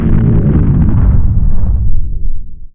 nuke.wav